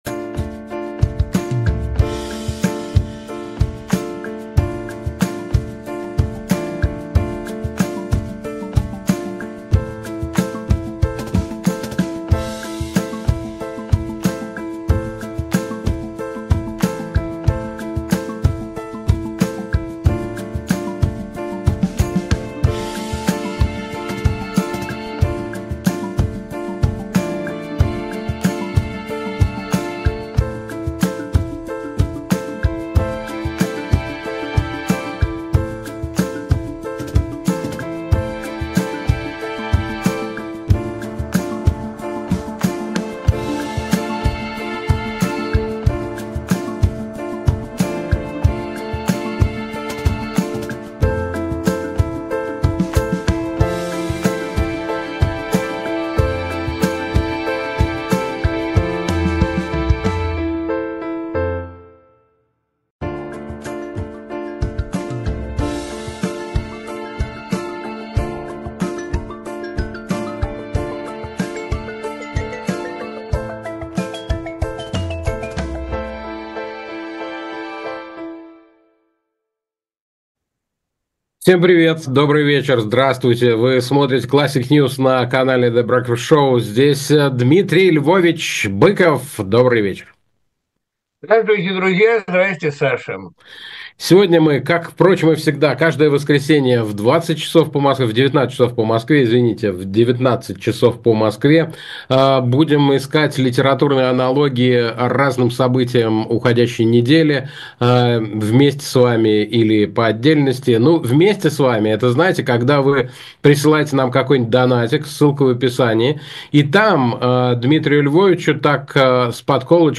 Эфир ведут Александр Плющев и Дмитрий Быков